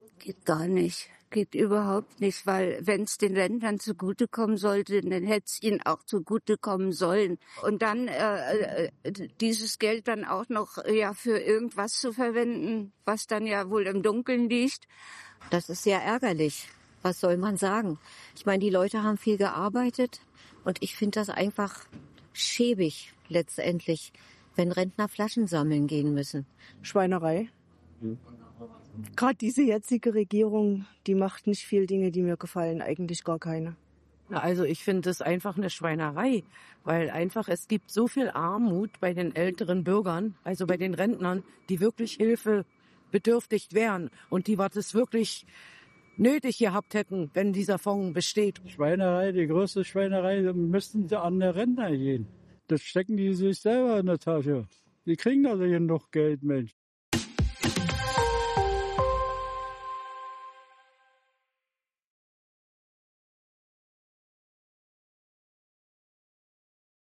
Und stieß dabei immer auf die gleiche Reaktion: unverhohlene Wut.